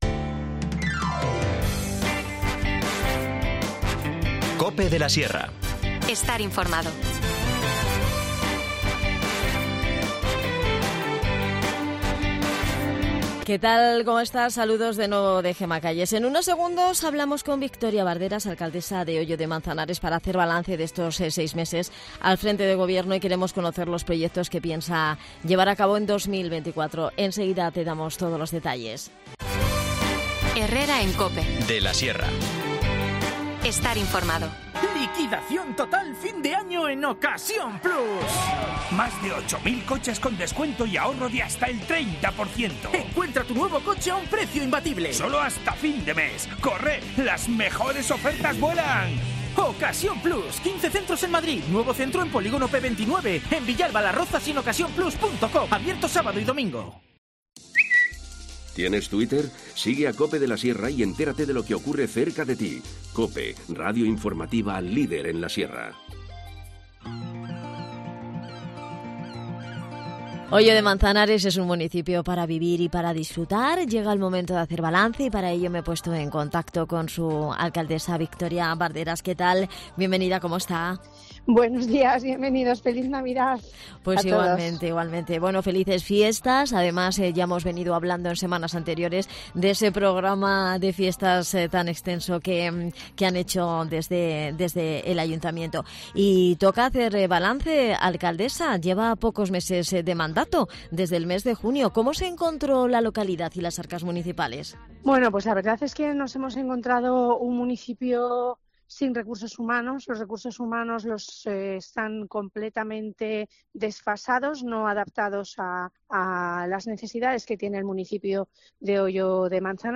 Hablamos con la alcaldesa de Hoyo de Manzanares, Victoria Barderas, para conocer las inversiones y los proyectos que quiere poner en marcha para el nuevo año.